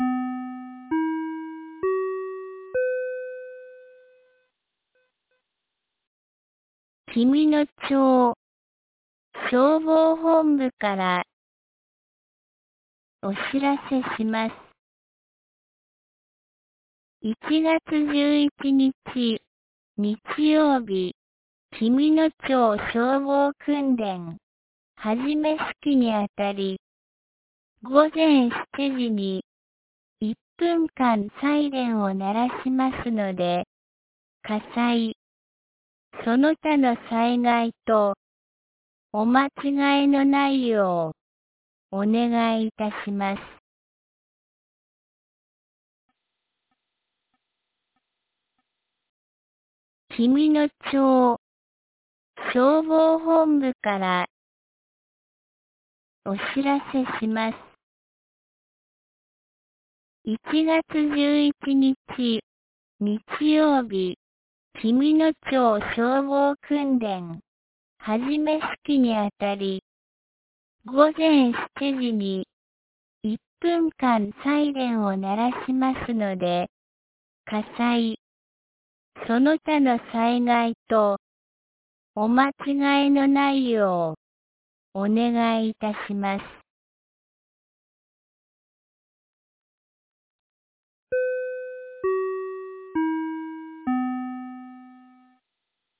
2026年01月10日 12時06分に、紀美野町より全地区へ放送がありました。